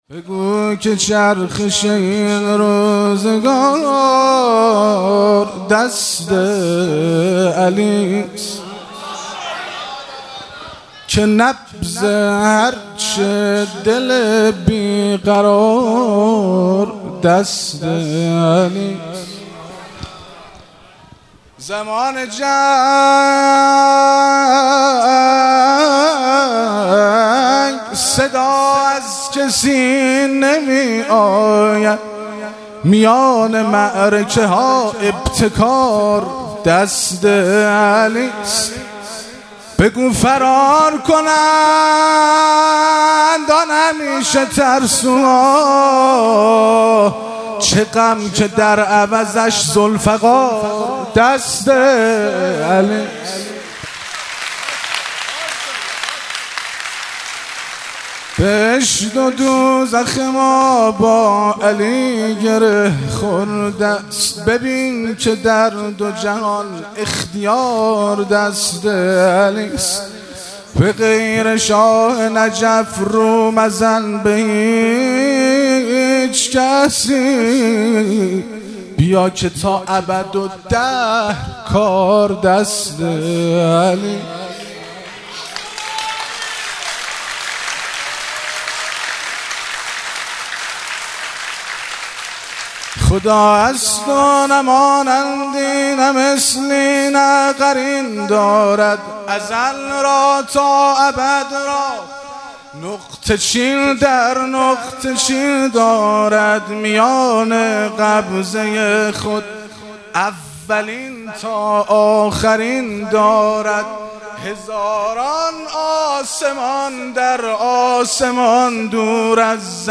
مراسم جشن شب دوم ویژه برنامه عید سعید غدیر خم 1444